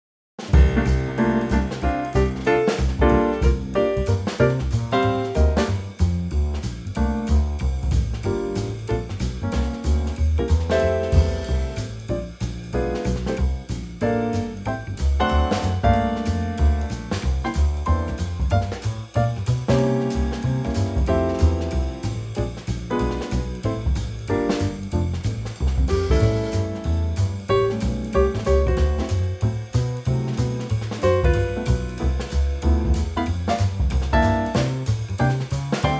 key F
Superb Trio arrangement
-Unique Backing Track Downloads